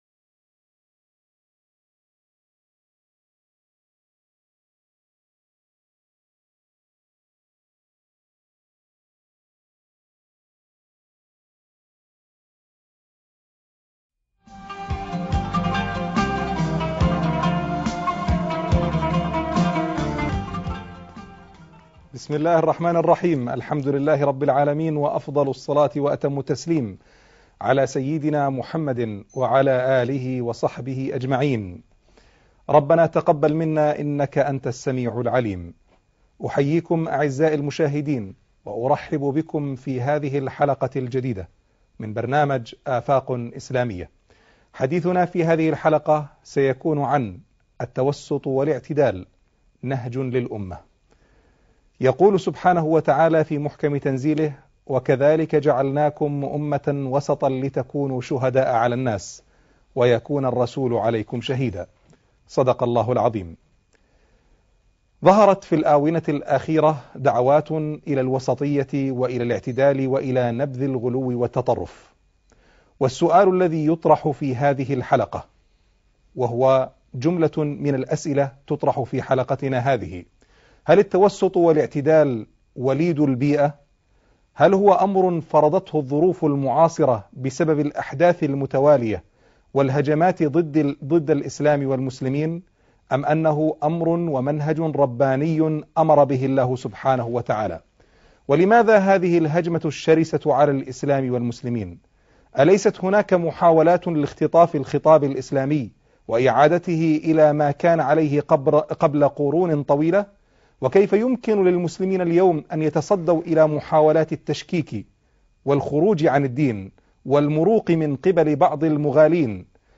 التوسط والاعتدال - لقاء خاص - الشيخ محمد راتب النابلسي